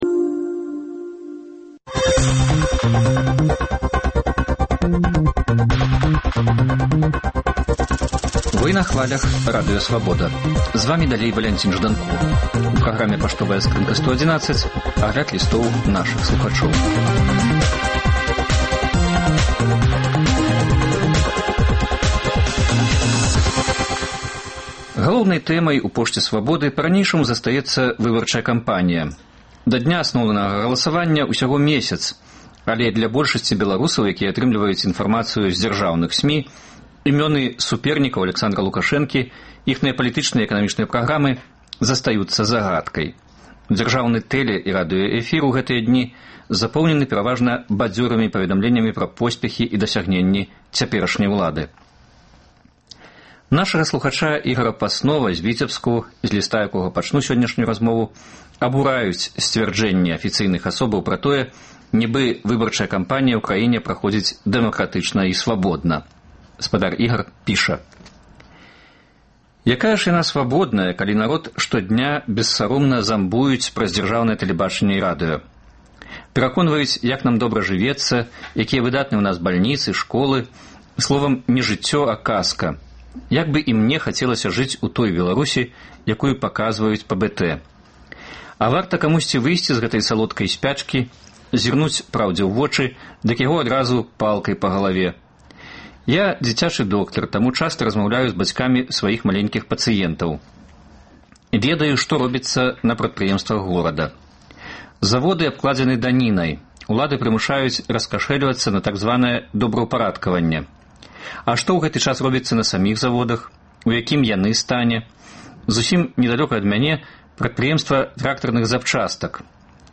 Гэты ды іншыя лісты слухачоў Свабоды чытае і камэнтуе